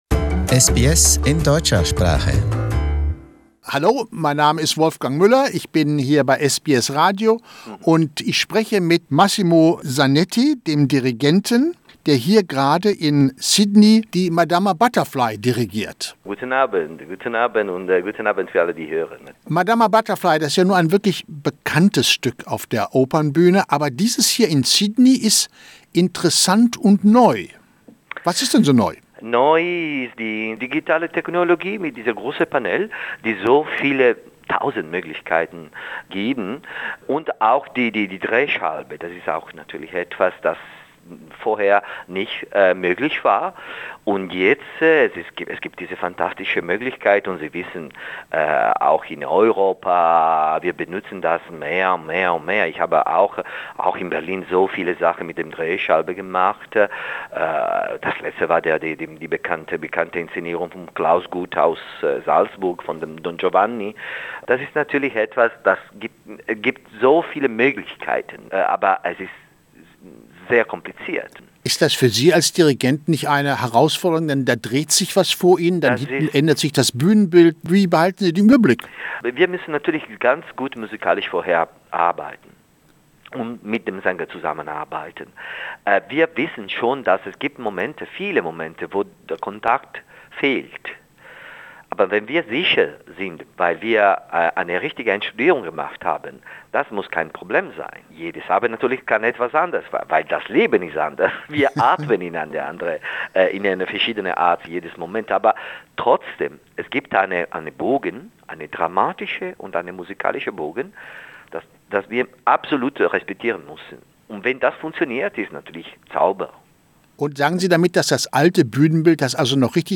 Italian conductor Massimo Zanetti talks enthusiastically of Graeme Murphy’s production and, in this interview, he says that this is one of the best Madama Butterfly’s productions he has seen in his long worldwide career Massimo Zanetti dirigiert Madama Butterfly Source: Opera Australia Share